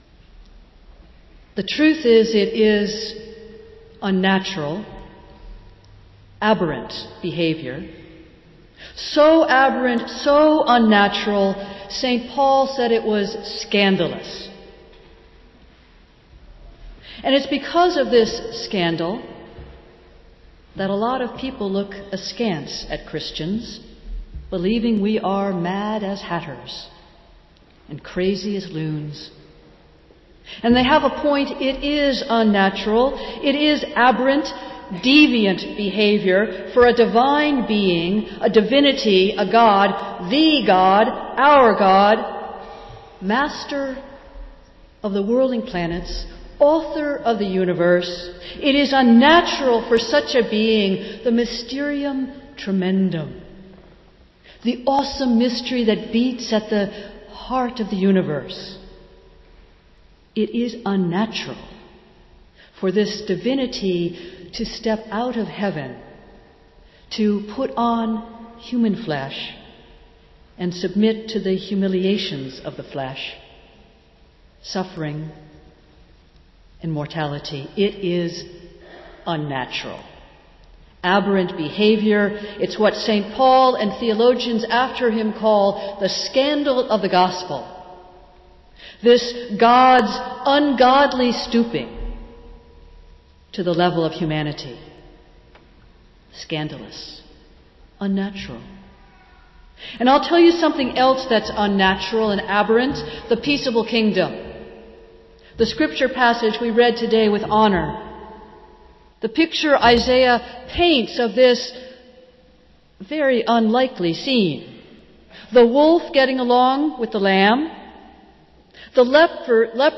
Festival Worship - Second Sunday in Advent